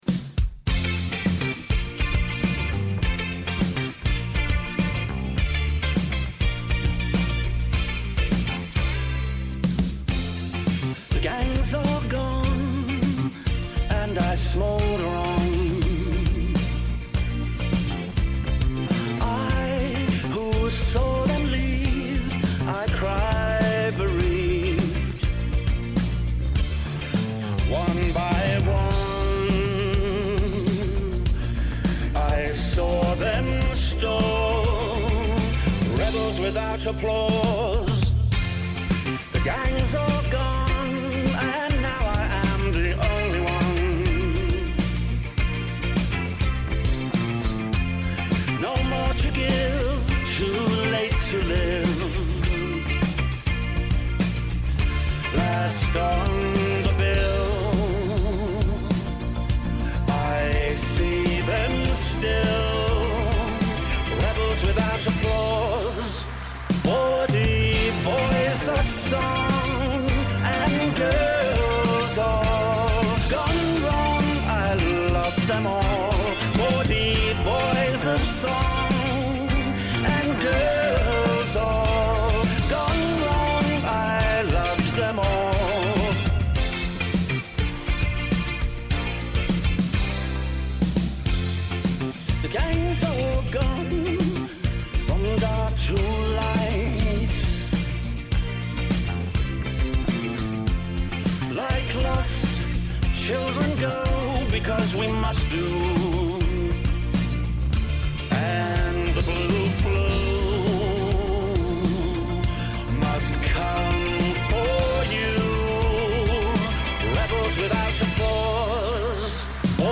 Η μακροβιότερη εκπομπή στο Ελληνικό Ραδιόφωνο!